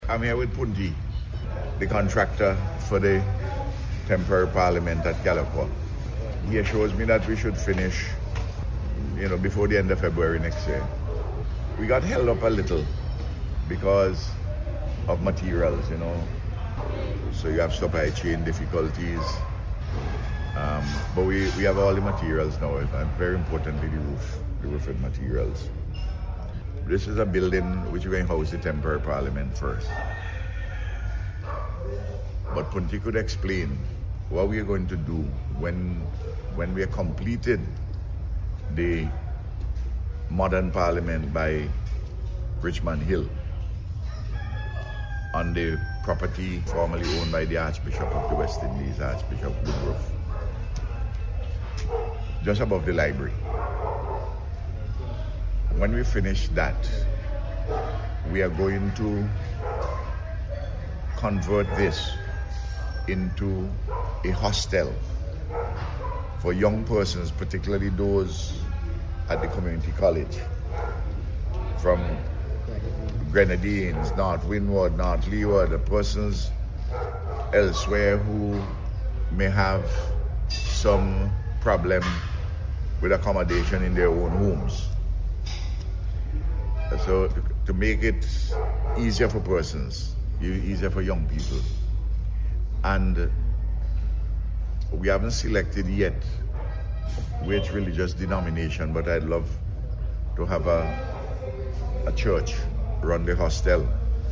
Prime Minister Dr. Ralph Gonsalves this week visited the site of the Temporary Parliament Building, which is being constructed at Calliaqua.
The Prime Minister provided a status update on the project to Members of the media.